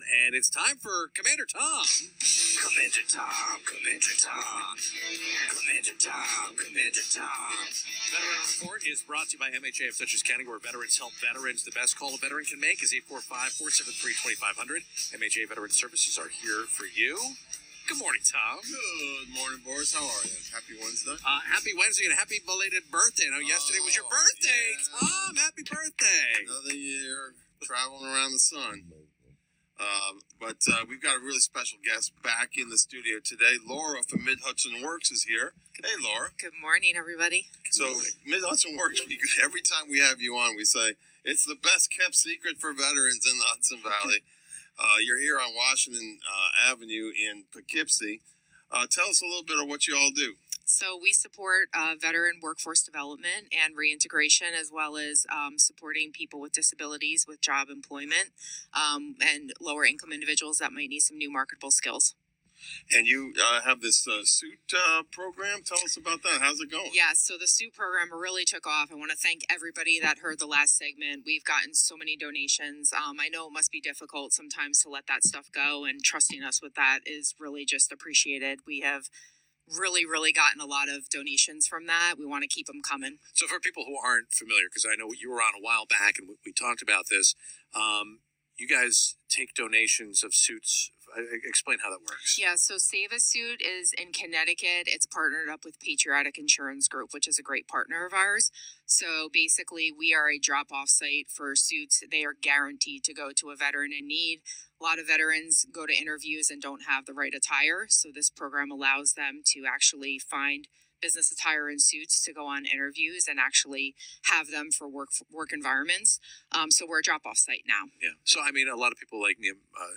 MHW-Radio-Program-Guesting.mp3